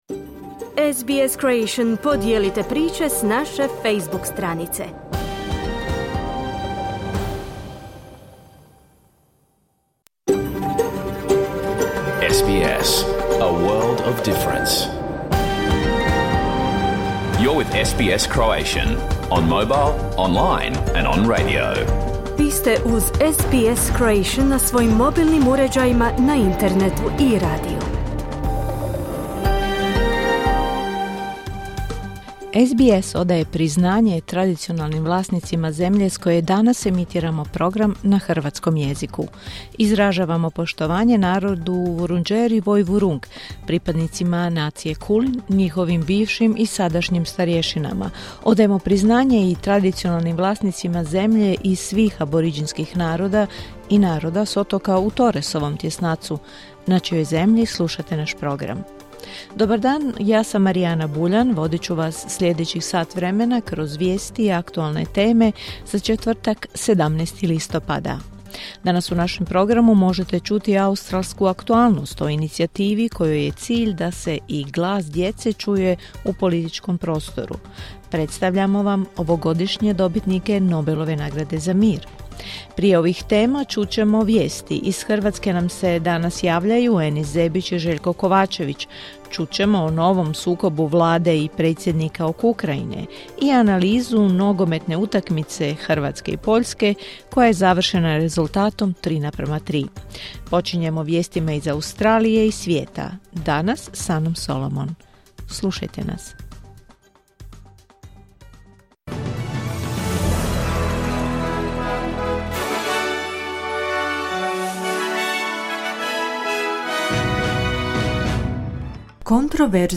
Vijesti i aktualnosti iz Australije, Hrvatske i svijeta. Emitirano uživo na radiju SBS1 u četvrtak, 17. listopada, u 11 sati po istočnoaustralskom vremenu.